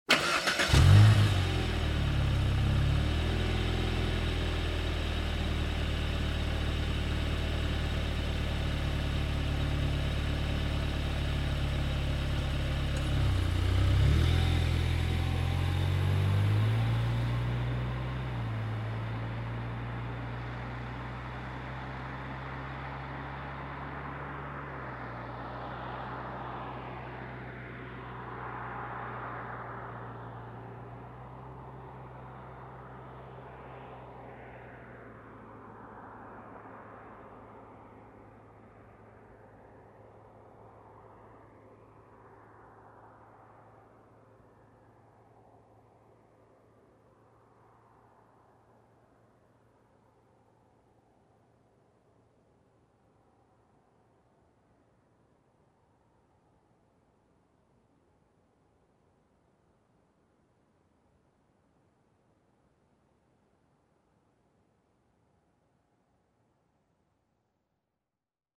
Звуки заводящейся машины - скачать и слушать онлайн бесплатно в mp3